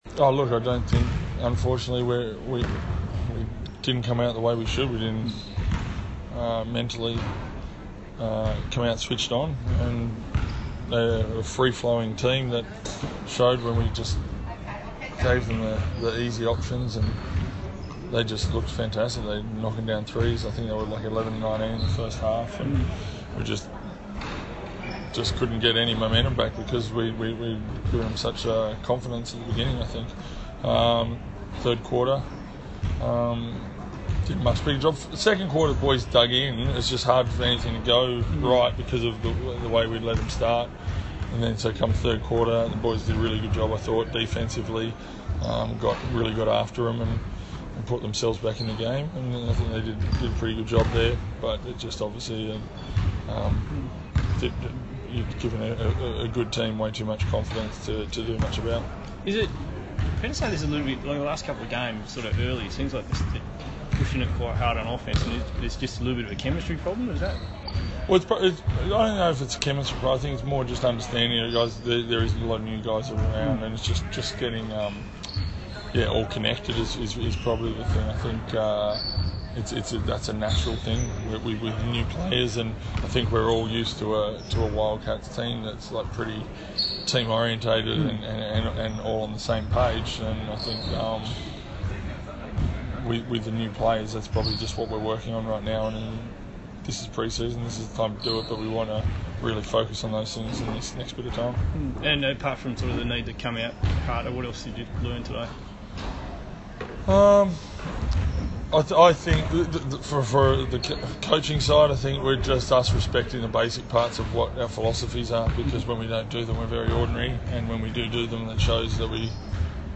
Matt Nielsen spoke to the media after the Wildcats loss to the Illawarra Hawks at the Australian Basketball Challenge.